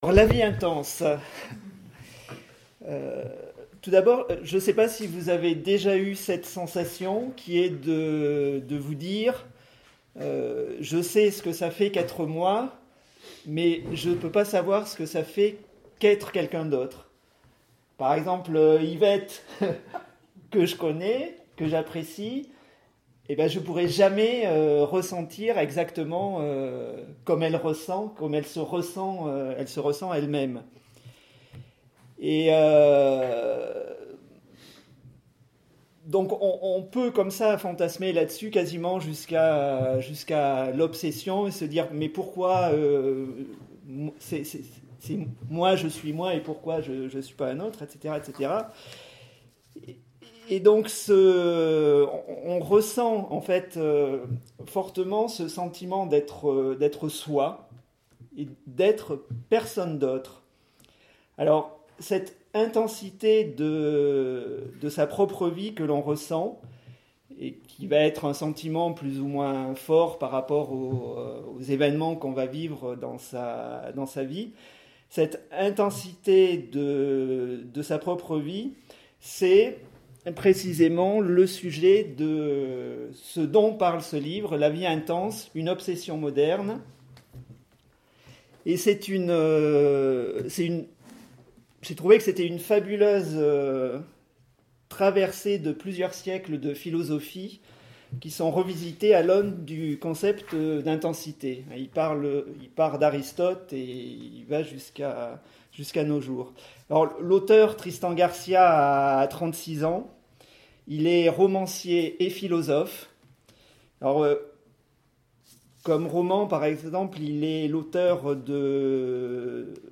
Enregistrement audio de la présentation :
Présentation de livres contemporains de philosophie